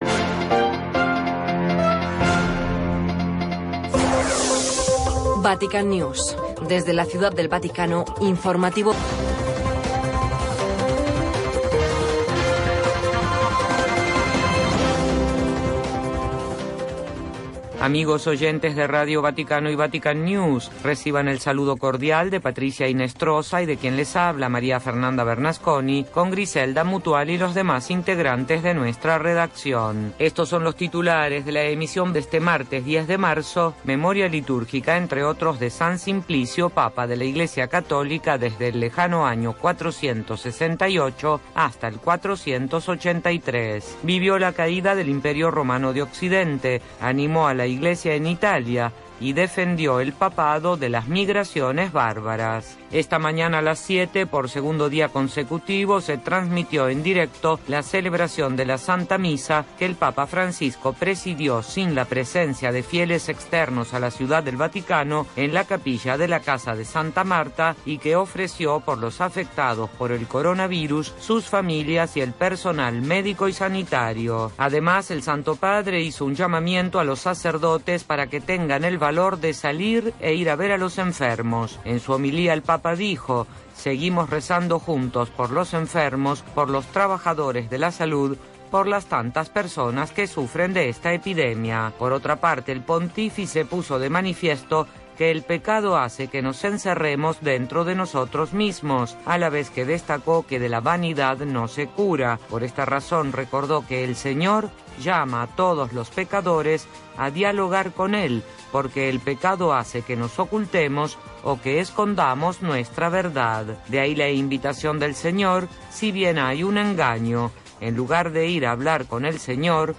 Radio Vaticana. L'informatiu en castellà de Ràdio Vaticana. Tota l'activitat del pontífex, com també totes aquelles notícies de Roma.